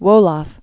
(wōlŏf)